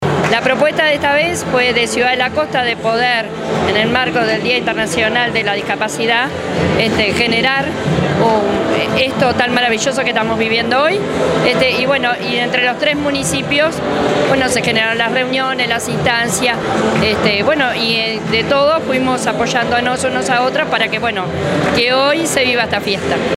En tanto, la Alcaldesa del Municipio Paso Carrasco, Verónica Veiga, manifestó que a pesar de que se trató de la primera edición, espera que haya muchas más.
alcaldesa_del_municipio_paso_carrasco_veronica_veiga.mp3